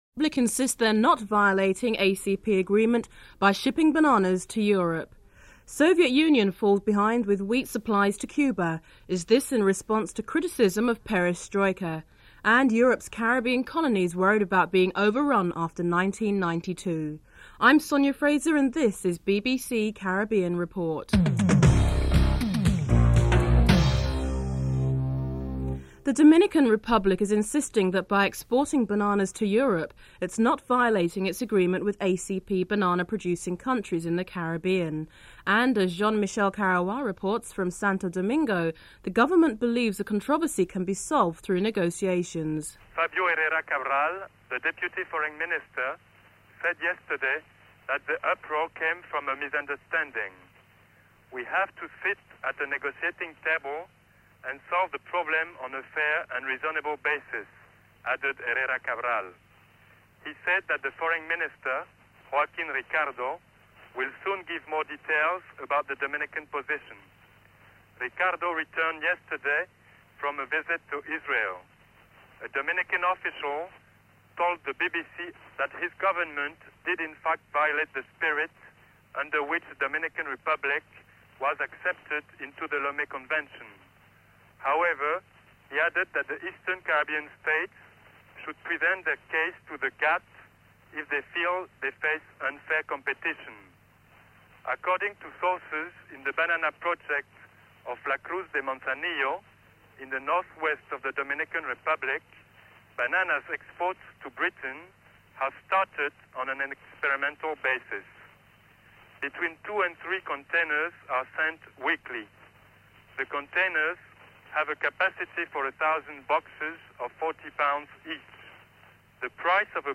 1. Headlines (00:00 - 00:25)